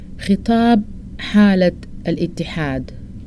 Sudanese Arabic Vocabulary List